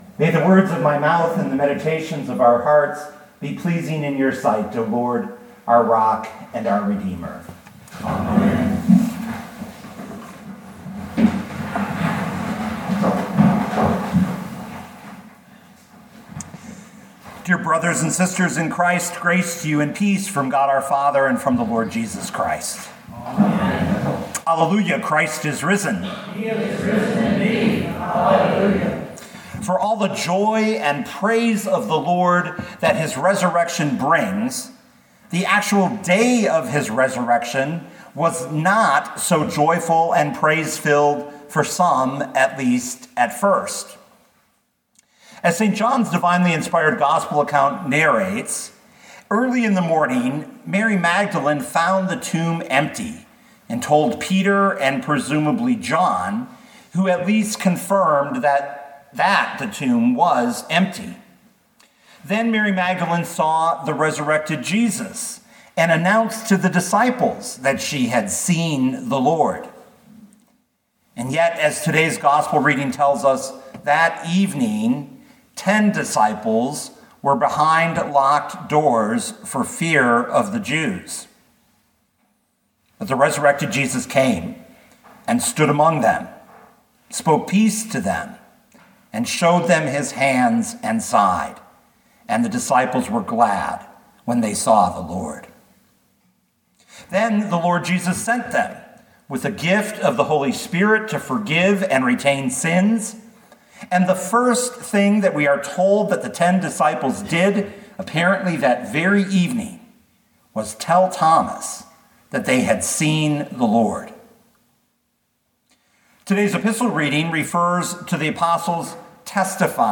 2024 John 20:19-31 Listen to the sermon with the player below, or, download the audio.